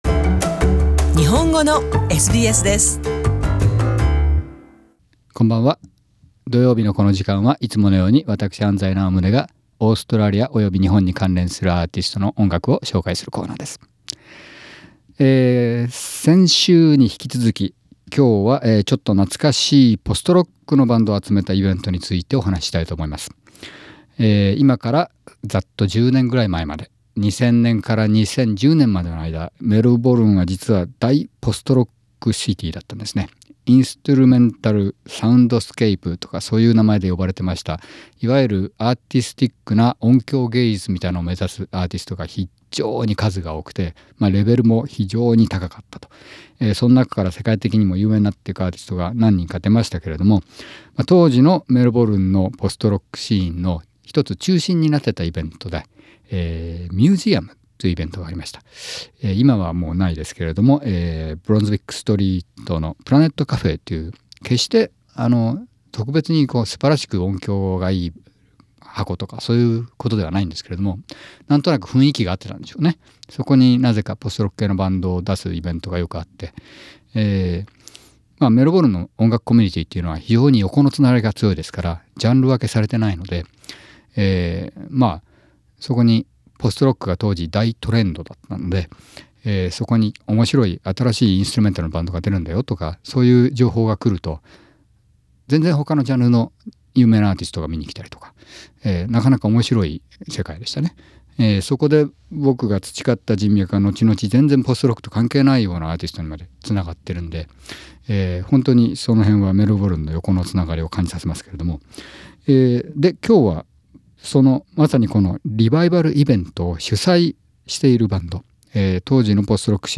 Melbourne's post-rock band.